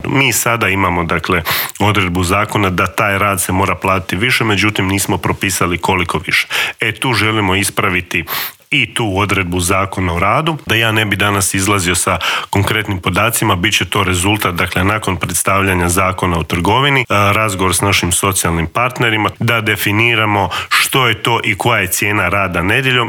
ZAGREB - Gostujući u Intervjuu tjedna Media servisa ministar rada, mirovinskoga sustava, obitelji i socijalne politike Marin Piletić otkrio je detalje pregovora sa sindikatima oko povišica, ali se osvrnuo i na najavu zabrane rada nedjeljom, kritikama na novi Zakon o radu, kao i o novostima koje stupaju na snagu 1. siječnja, a tiču se minimalne plaće i mirovina.